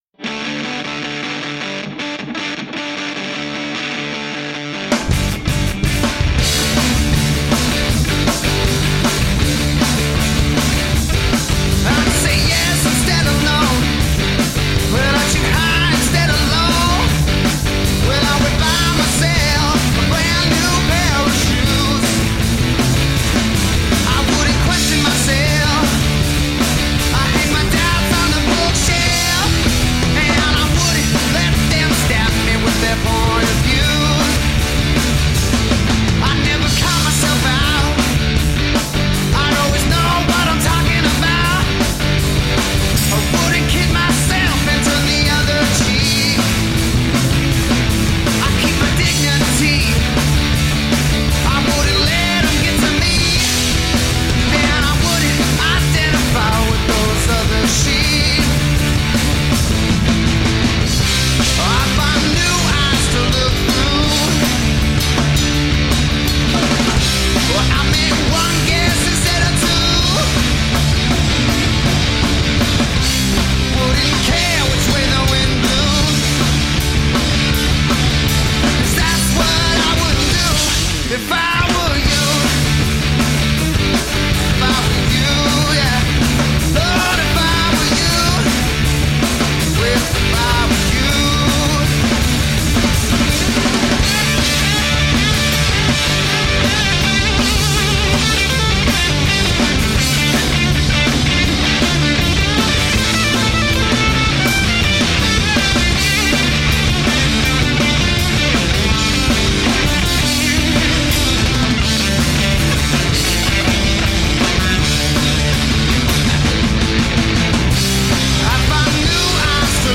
Original, blues-based rock and roll.
Tagged as: Hard Rock, Metal